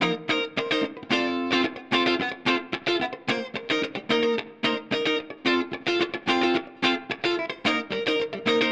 30 Guitar PT1.wav